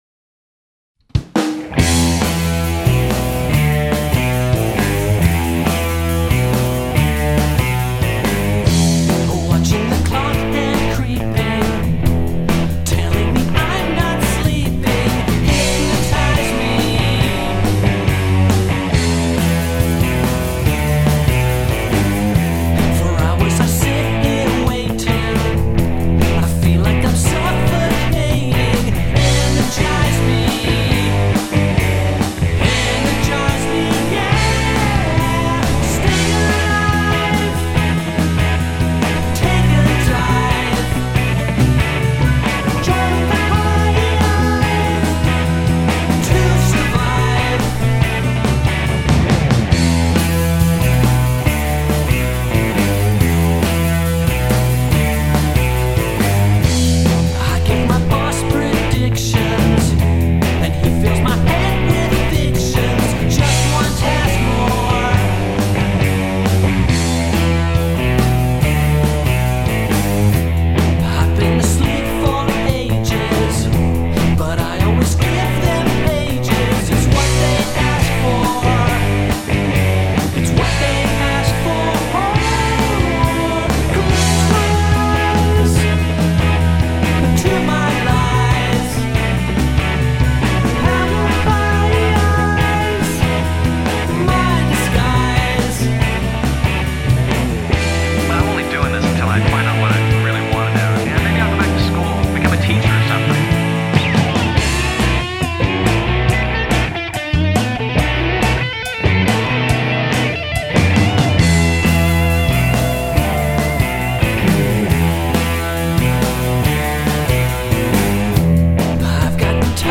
It sets the stage for the rest of the operetta, insofar as it’s spiteful and depressing.
Please note that this track, like all the others you’ll be hearing, is a demo. So it’s got some rough production edges.